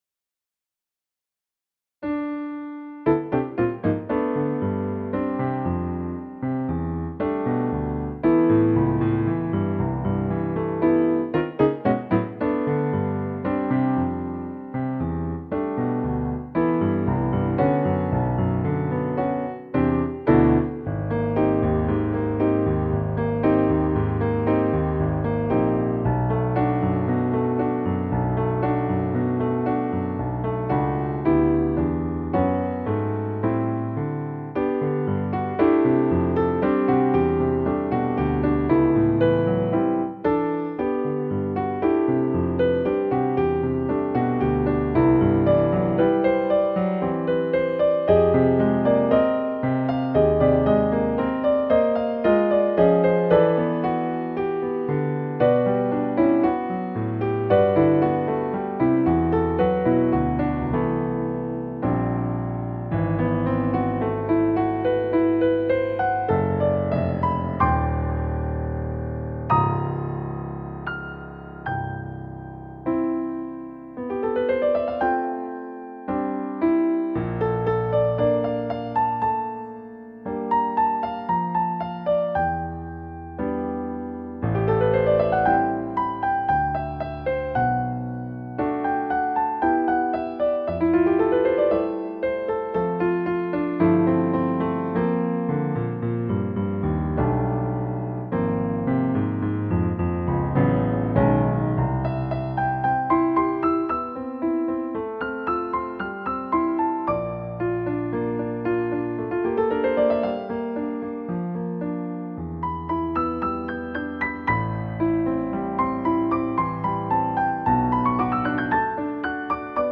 Summer Music piano